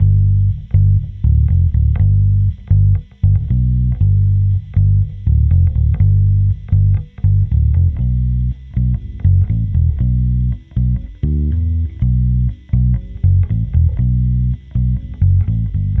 宇宙低音吉他第二部分
描述：k (单声道录音...)
Tag: 120 bpm Rock Loops Bass Guitar Loops 2.69 MB wav Key : G